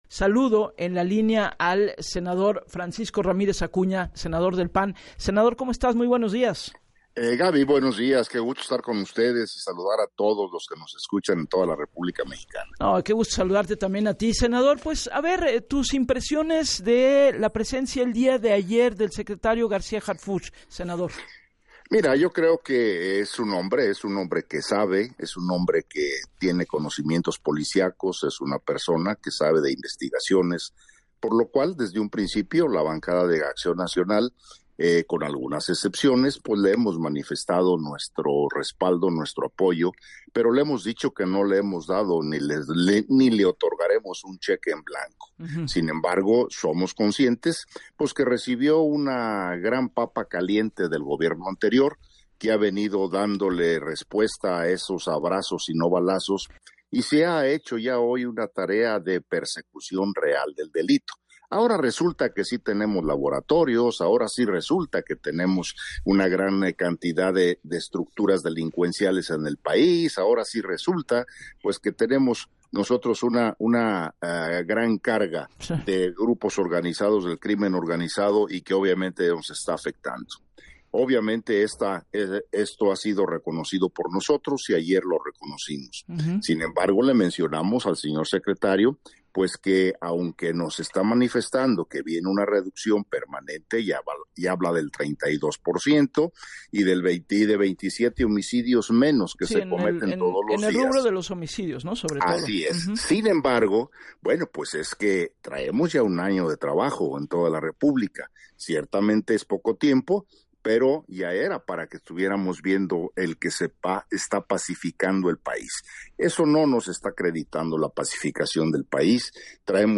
En entrevista con Gabriela Warkentin, el legislador panista reconoció que Harfuch “recibió una gran papa caliente del gobierno anterior y ha venido dándole respuesta a esos abrazos no balazos con una persecución real del delito”.